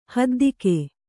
♪ haddike